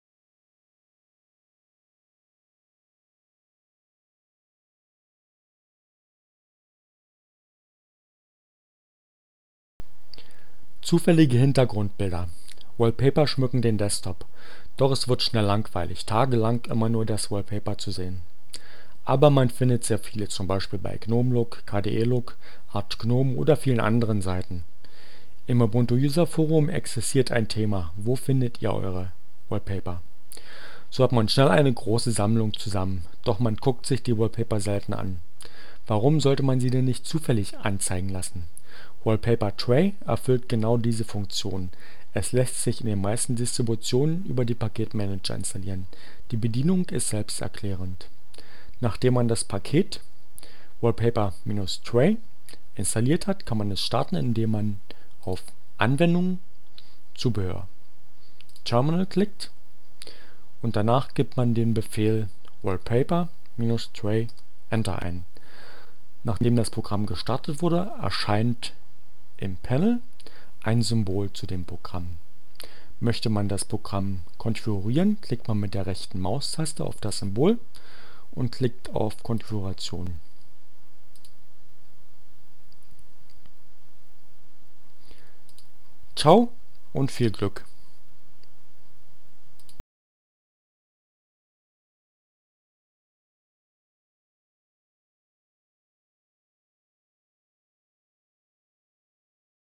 Tags: CC by-sa, Gnome, Linux, Neueinsteiger, Ogg Theora, ohne Musik, screencast, ubuntu, wallpaper, wallpaper-tray